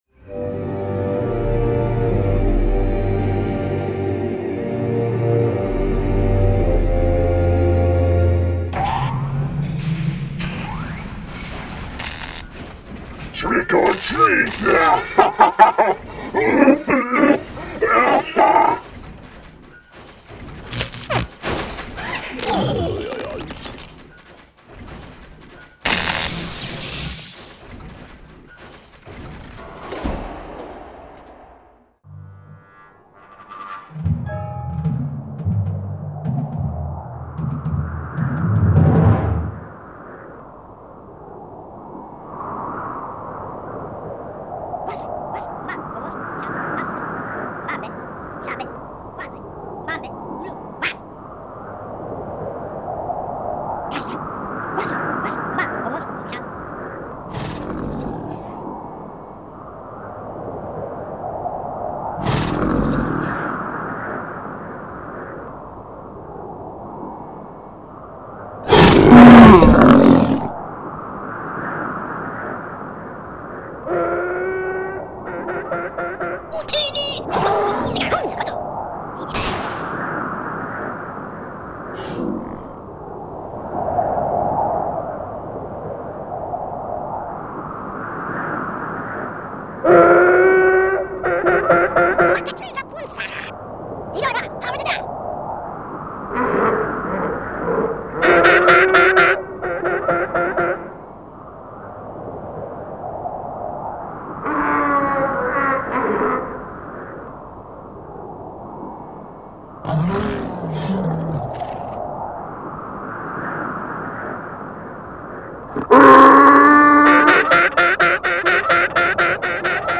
Perfect for Halloween, featuring creepy creatures and thrilling galactic growls.
Add some scary sounds to your haunted house with this Star Wars Halloween MP3! It’s jam-packed with horrifying sounds from creepy creatures, as well as the evil General Grievous, from Star Wars!